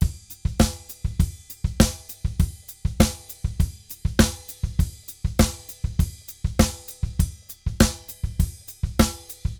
Drums_Samba 100_3.wav